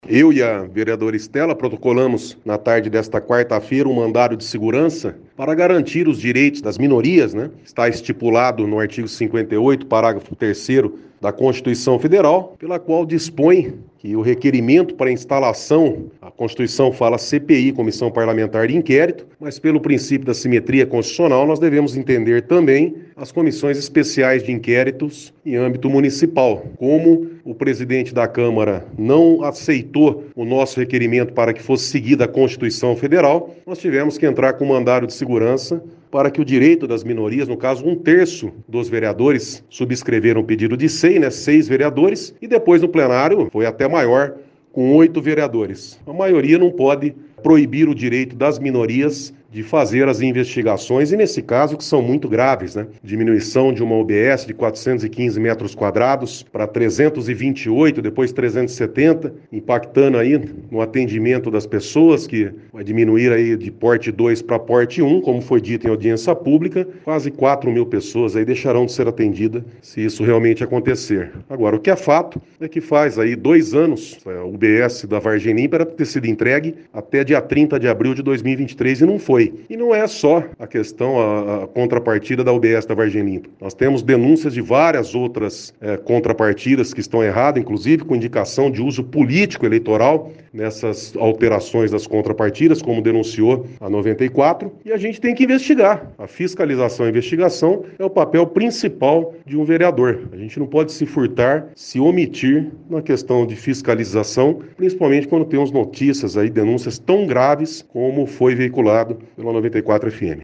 Eduardo Borgo afirmou em entrevista à 94FM, que esse pedido de mandado de segurança foi feito para que o direito das minorias seja respeitado. Vamos ouvir o vereador.